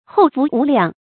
后福无量 hòu fú wú liàng
后福无量发音